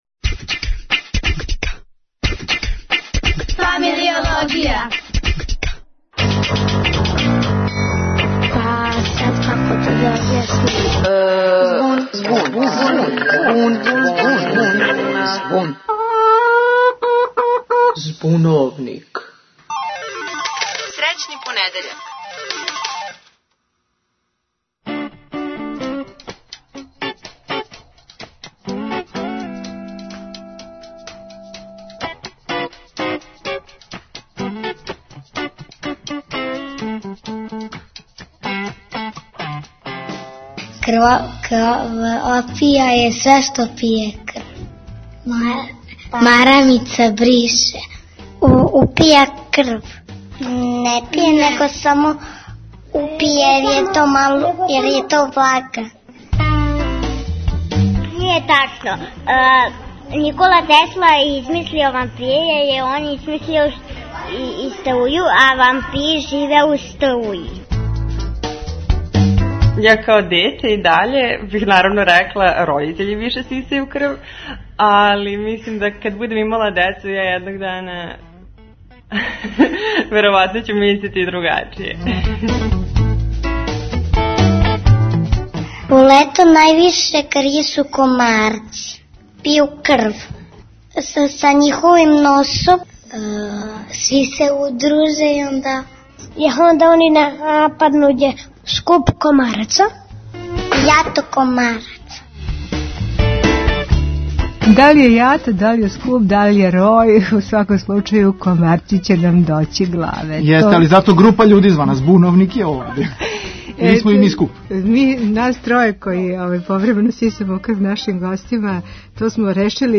Студенти фармације и медицине нам говоре о томе да ли је лакше кад вам неко узима крв или када је ви узимате некоме.